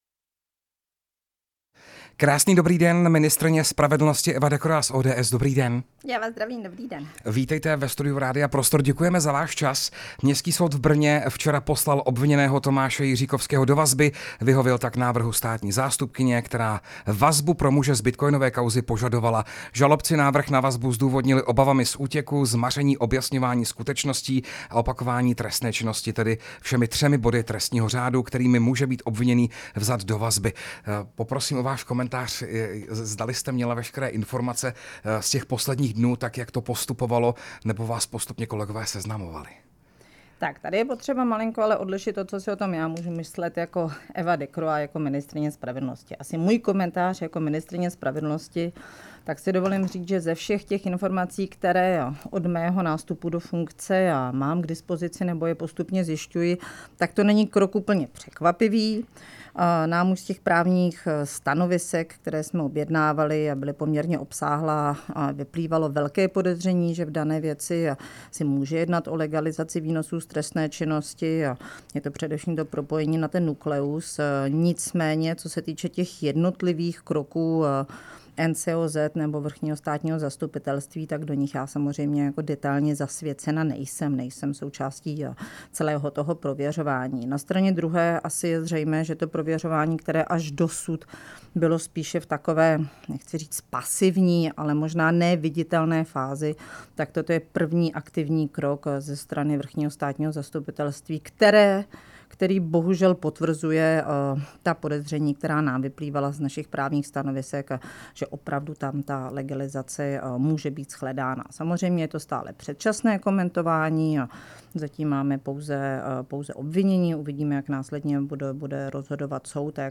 Hostem pondělního Prostoru pro dva byla ministryně spravedlnosti Eva Decroix (ODS).
Rozhovor s ministryní spravedlnosti Evou Decroix